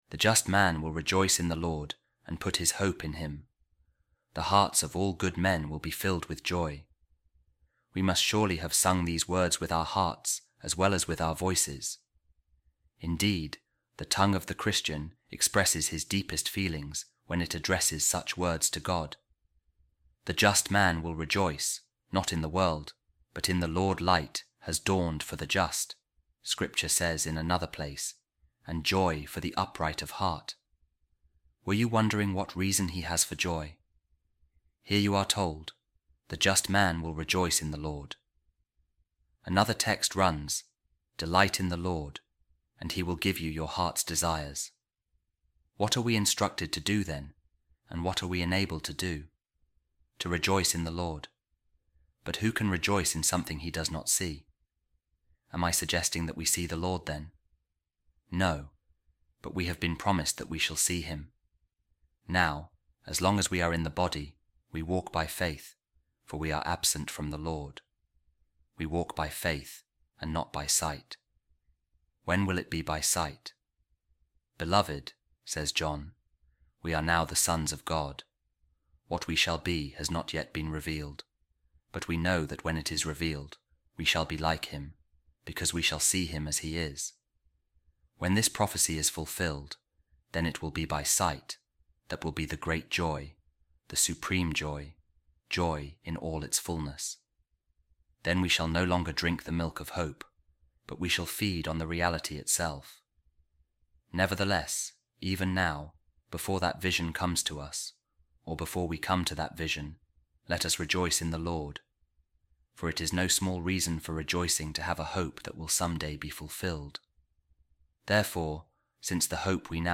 A Reading From The Sermons Of Saint Augustine | The Heart Of The Righteous Will Rejoice In The Lord